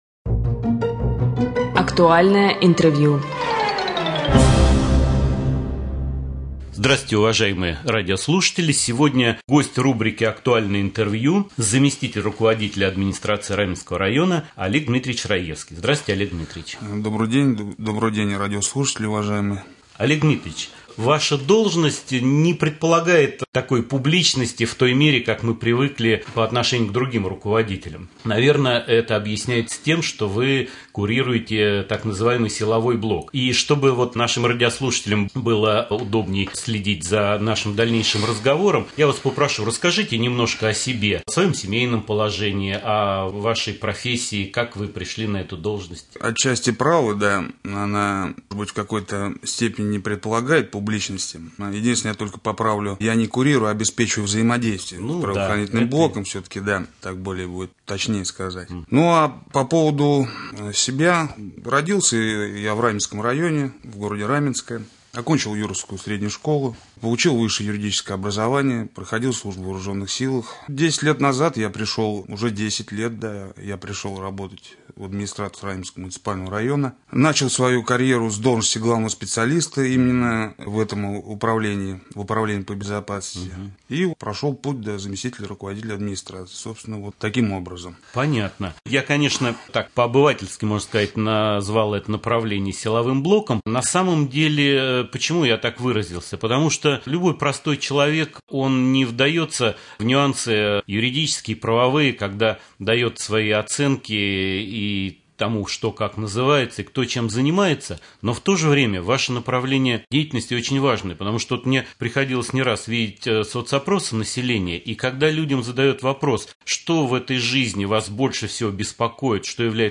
Гость студии заместитель руководителя администрации Раменского района Олег Дмитриевич Раевский.